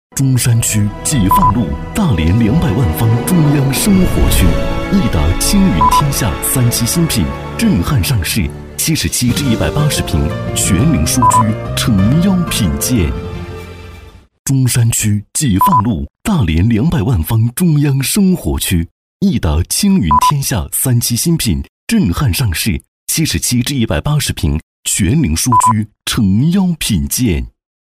男90-房地产广告《亿达·青云天下》-激情磁性.mp3